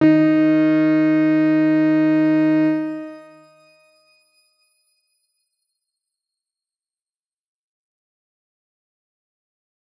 X_Grain-D#3-pp.wav